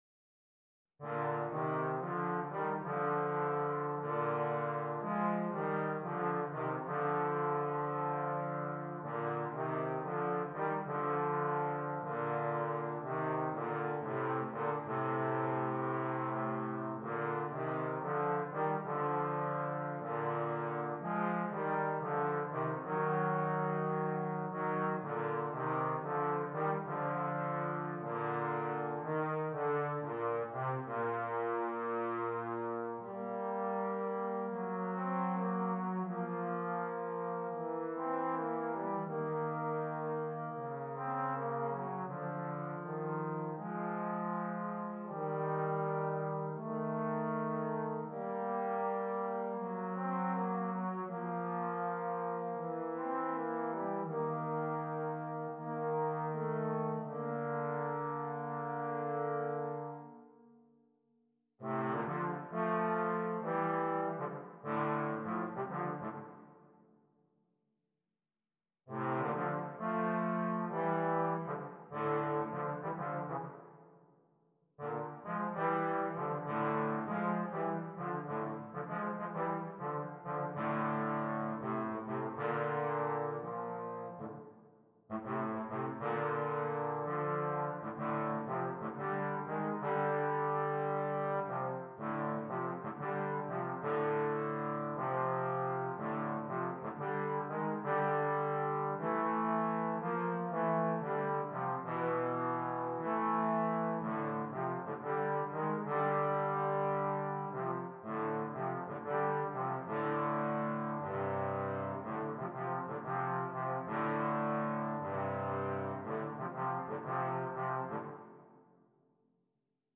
2 Trombones
A varied assortment of styles is offered for your merriment.